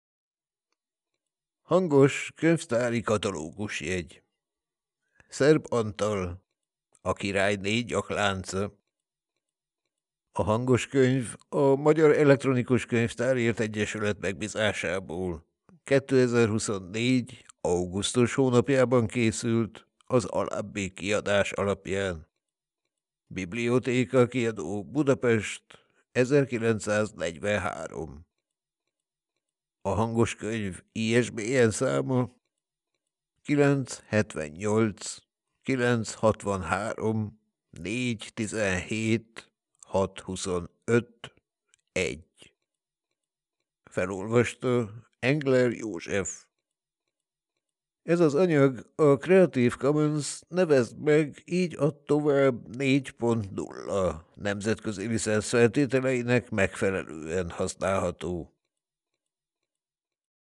HANGOSKÖNYV fájlok letöltése: